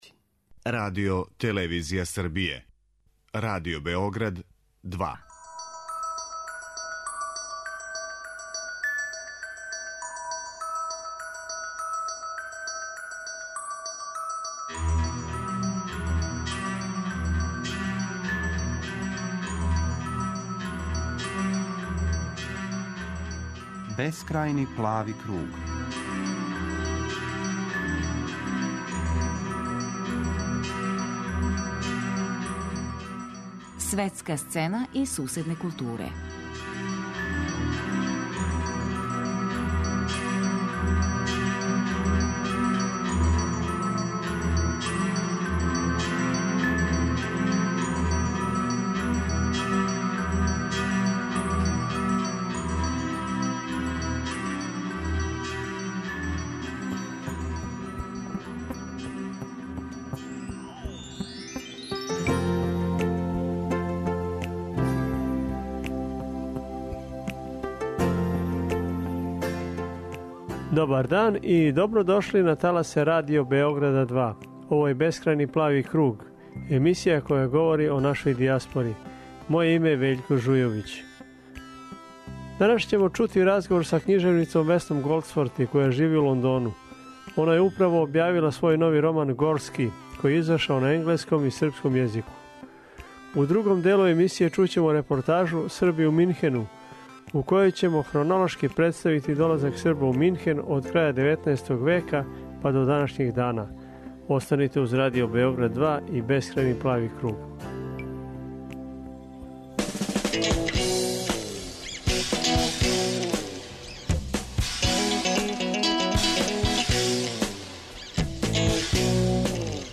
У данашњој емисији чућемо разговор са књижевницом Весном Голдсворти, која живи у Лондону.